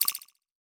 Percussion Echo Notification1.wav